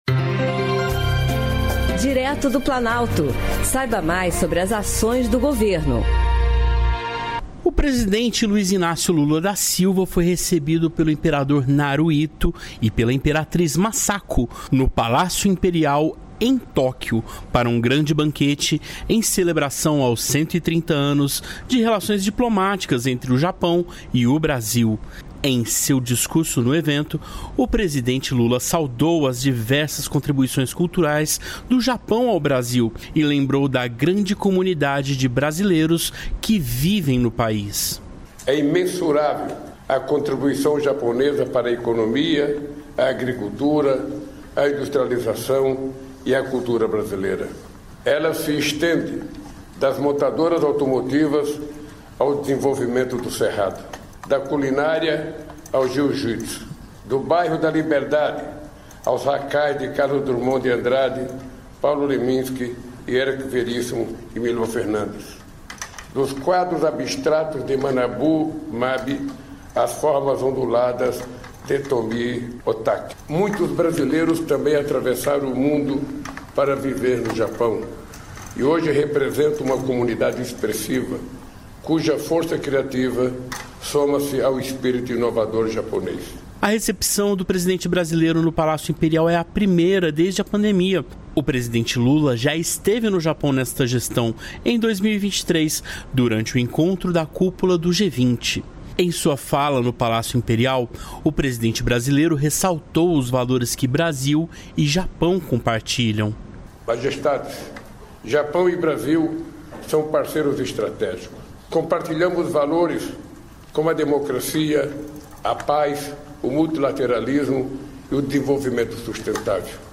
O presidente Luiz Inácio Lula da Silva foi recebido pelo imperador Naruhito e pela imperatriz Masako no Palácio Imperial, em Tóquio, para um grande banquete em celebração aos 130 anos de relações diplomáticas entre o Japão e o Brasil. Em discurso no evento, Lula saudou as diversas contribuições culturais do Japão ao Brasil e citou a grande comunidade brasileira que vive no país.